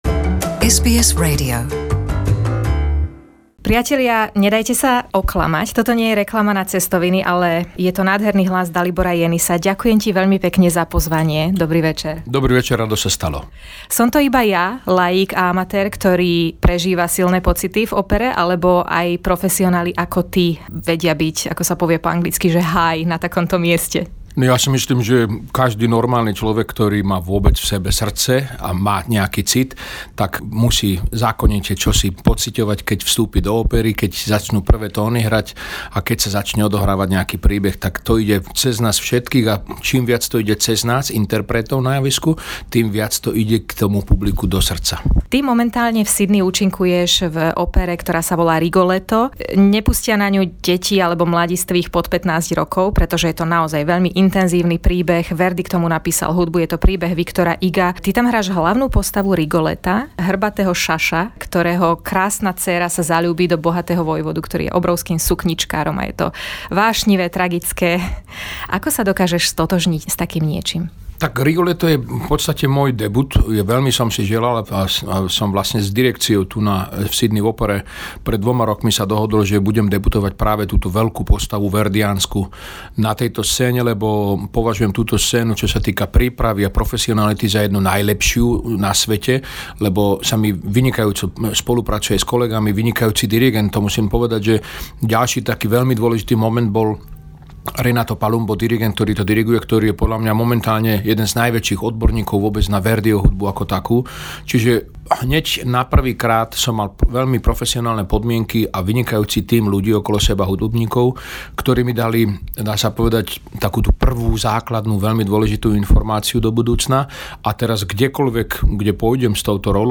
Exkluzívny rozhovor SBS so svetovým slovenským barytónom Daliborom Jenisom